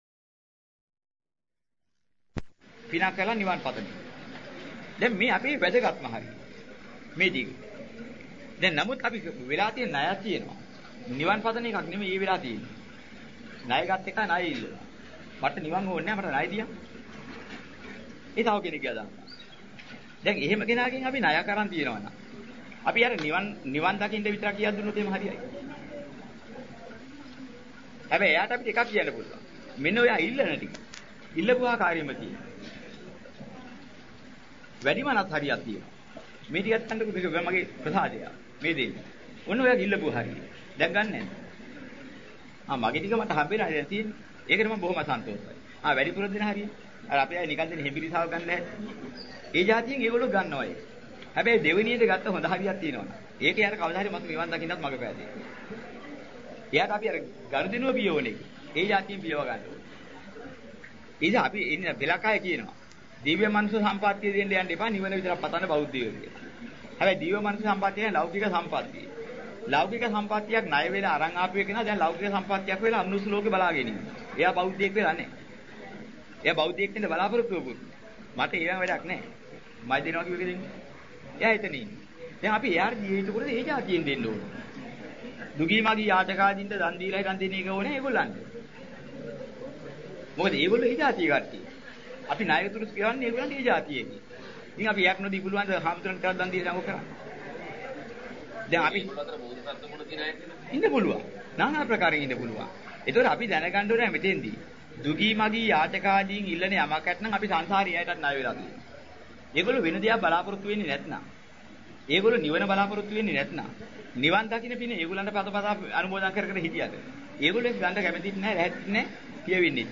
ධර්ම දේශනාවක කොටසකින් උපුටා ගන්නාලද දේශනාවකි. විවිධ අය ණය වී ඇති ආකාරය අනුව, නිවන් ප්‍රාර්ථනයෙන් ම පමණක් ණය ගෙවිය හැකිද?